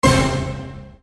Hit 003.wav